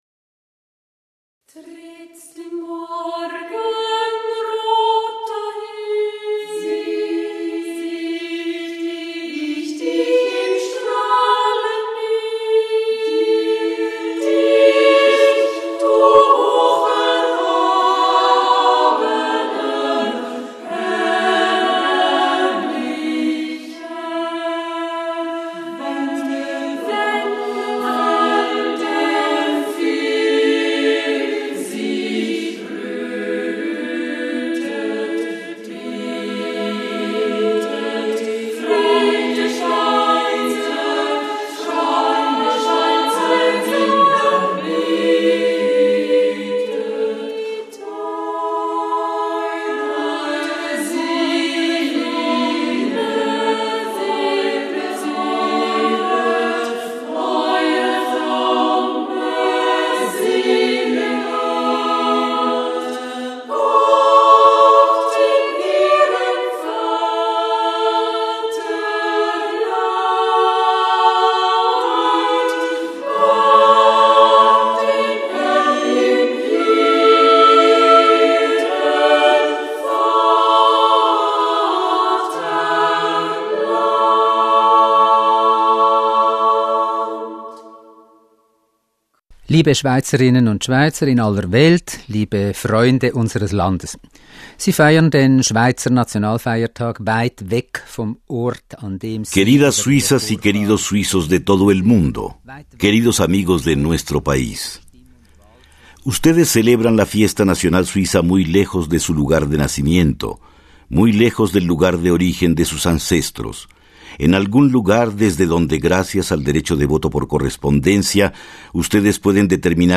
Con motivo del 1 de agosto, Fiesta Nacional de Suiza, el presidente de la Confederación, Moritz Leuenberger, se dirige a los compatriotas que viven en el exterior.